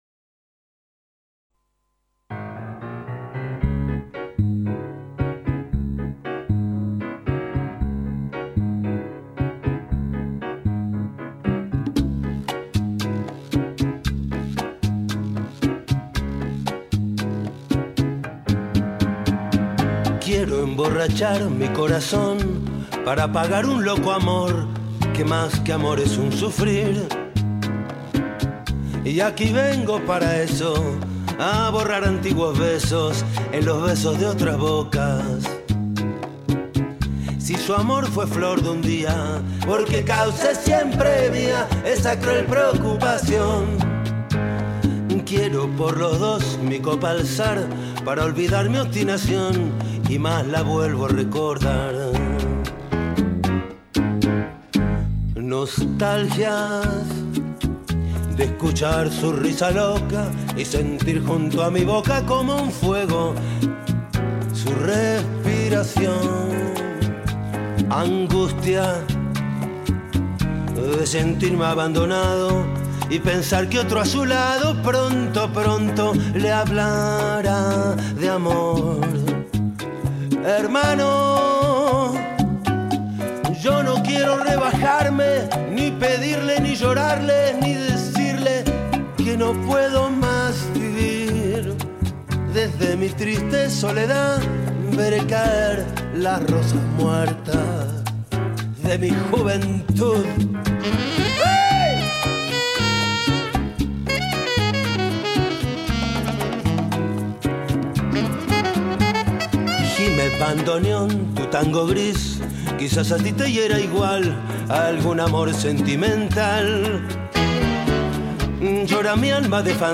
También en el Espacio de Bienestar, los funcionarios del Instituto de Información, el Portal de la Udelar, Psicología, y Humanidades, hablaron de sus nostalgias, a poco de celebrarse el 24 de noche la Fiesta de la Nostalgia.